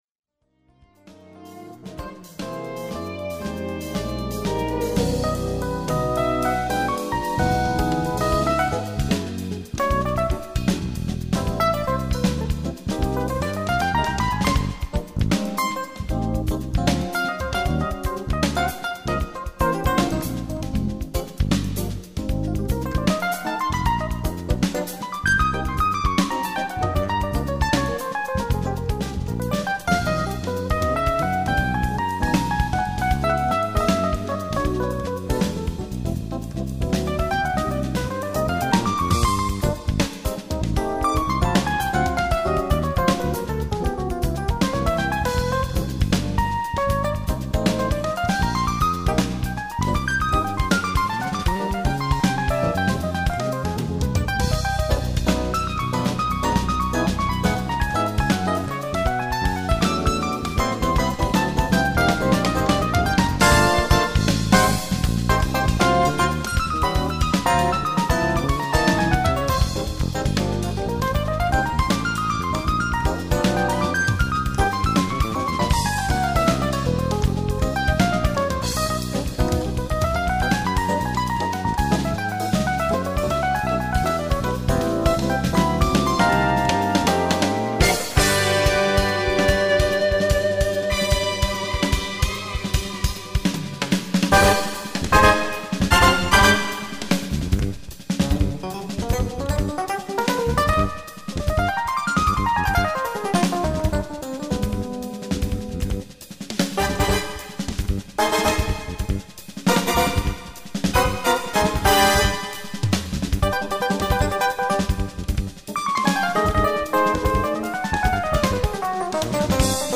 Latin en Fusion muziek,zijn vurige spel